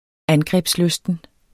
Udtale [ ˈangʁεbs- ]